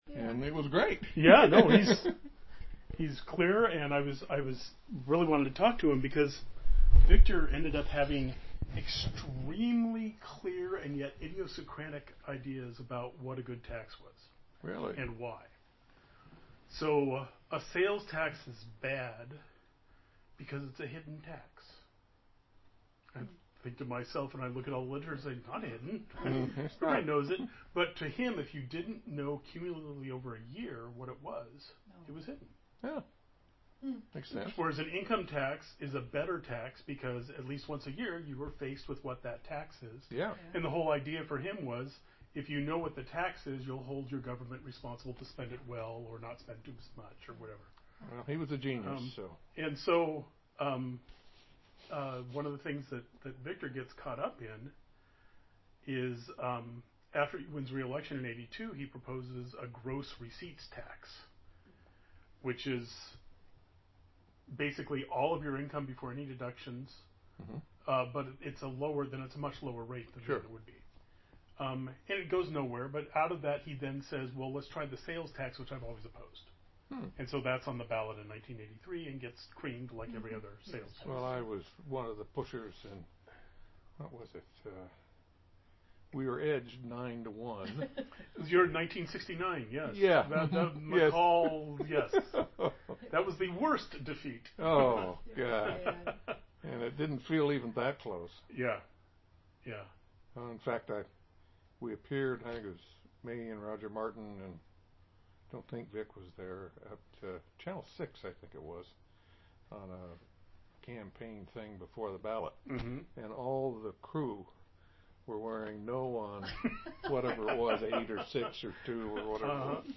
53c3ce5beda4ad92ae72b56257cc034703a1f18c.mp3 Title Wally Carson interview on Atiyeh, 2016 Description An interview of Wally Carson on the topic of Oregon Governor Vic Atiyeh, recorded on June 9, 2016. Carson served in Oregon's legislature alongside Atiyeh in the 1960s-70s, and was appointed to Oregon's Supreme Court by Atiyeh in 1982.